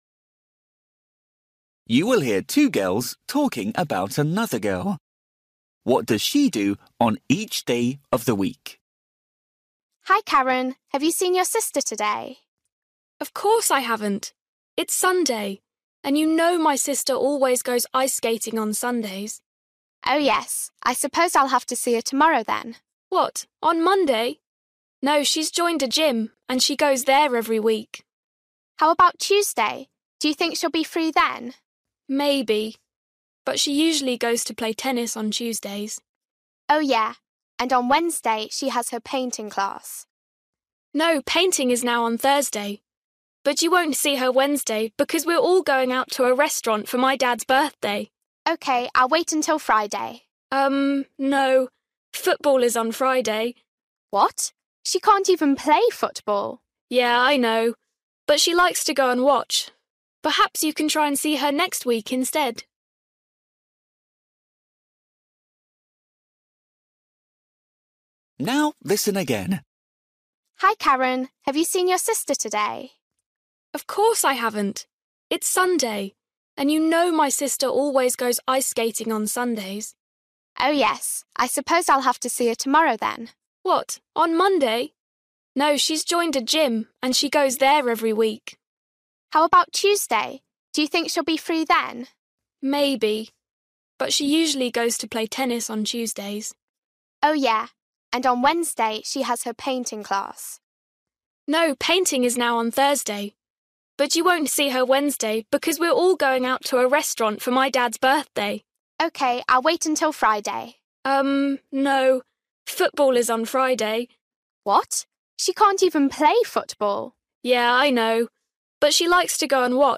You will hear two girls talking about another girl.